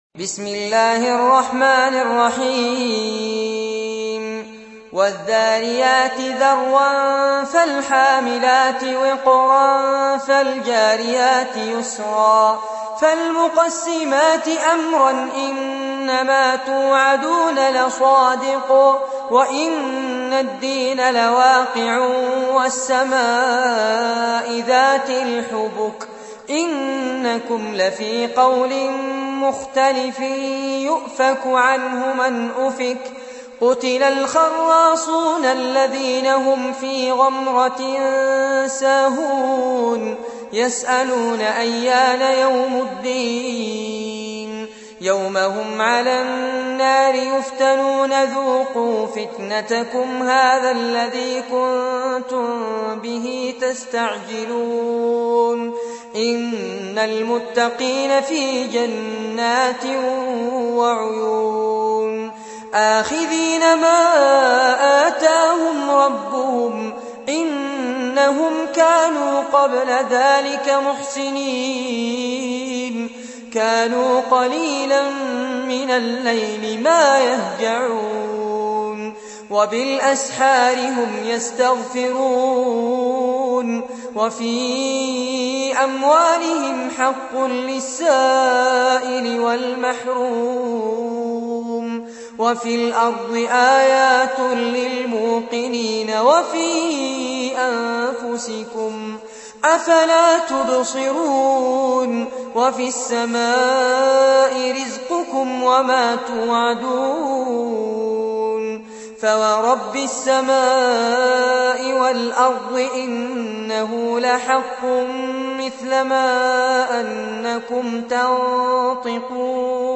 Récitation par Fares Abbad